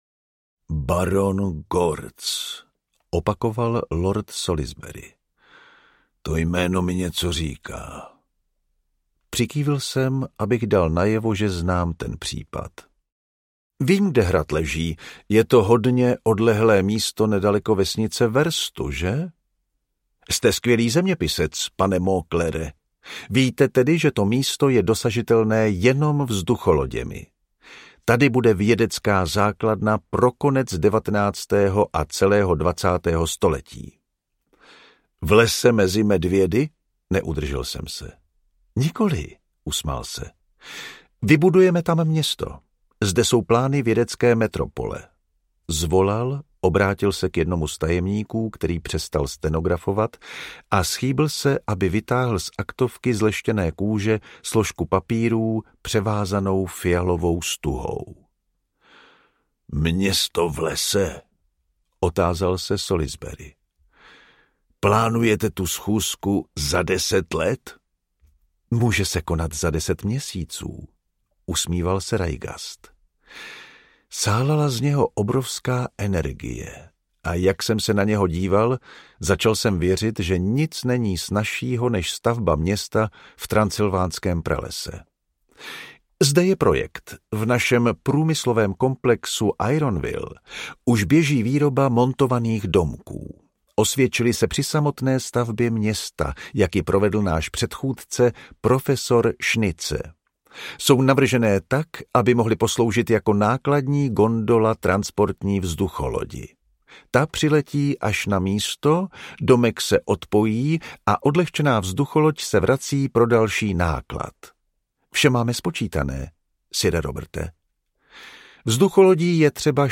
Pán vzduchu audiokniha
Ukázka z knihy
• InterpretJan Vondráček